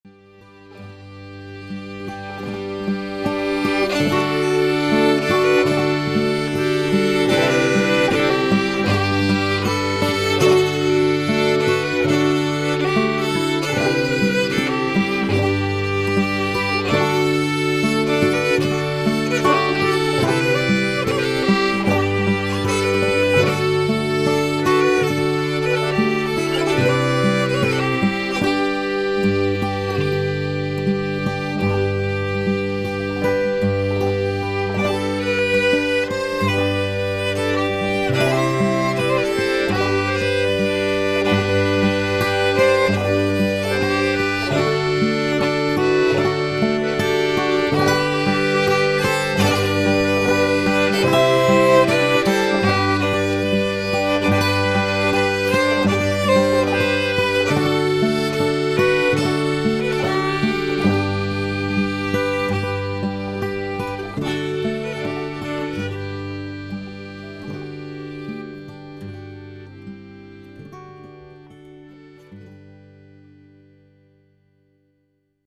The End of the Valley, guitar, accordion, fiddle and banjo.